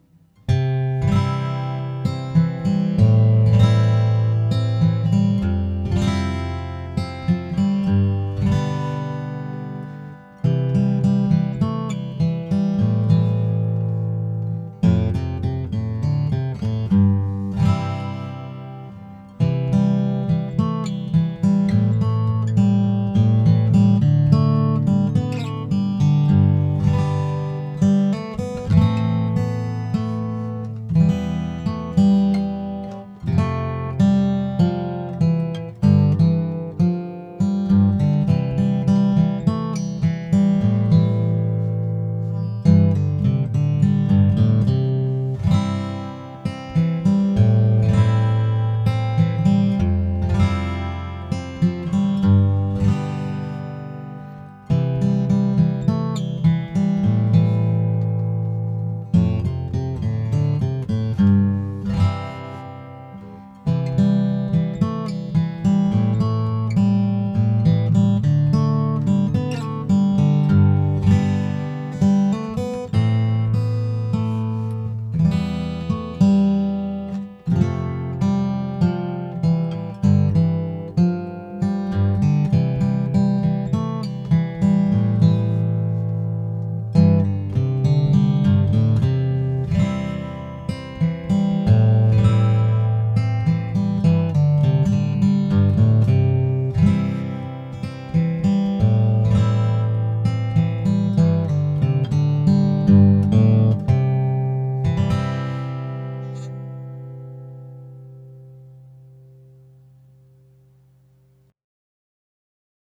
I like these playlists – as I like my set lists – to begin with an acoustic guitar instrumental.